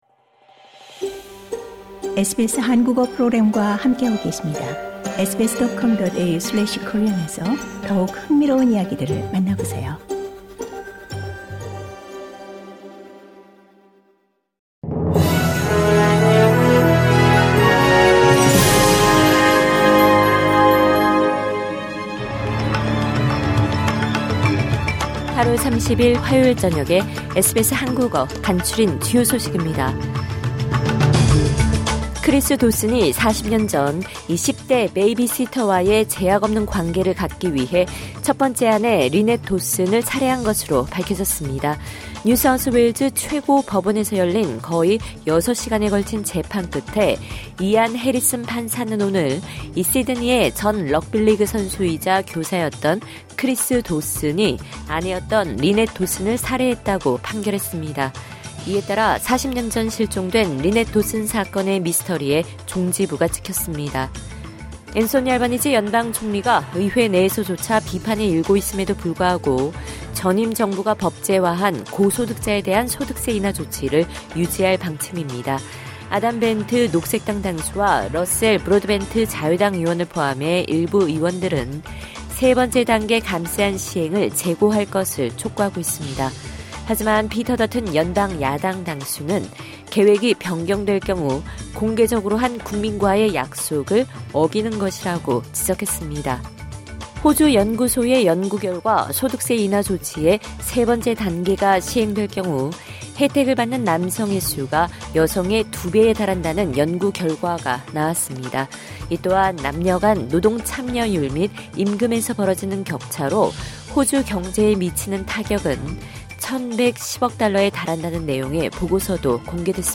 2022년 8월 30일 화요일 저녁 SBS 한국어 간추린 주요 뉴스입니다.